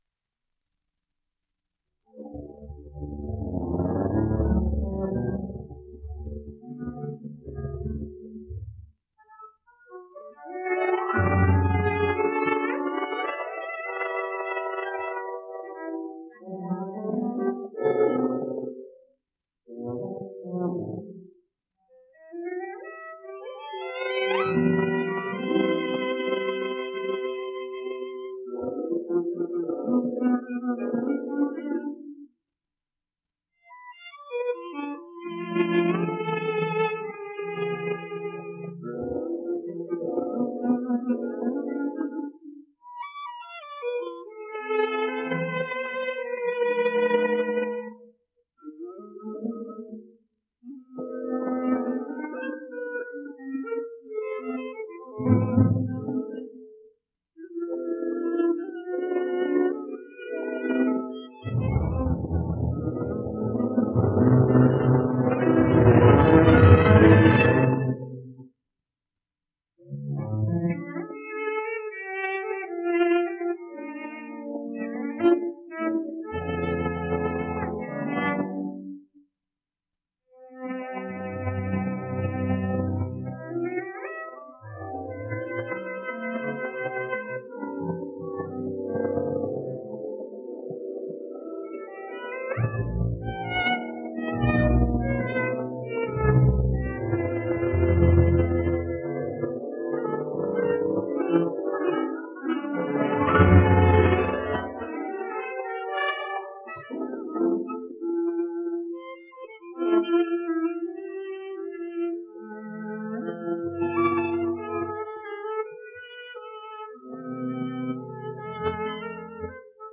Don Quixote, op. 35 : Fantastische Variationen über ein Thema ritterlichen Charakters, part IV (sonido remasterizado)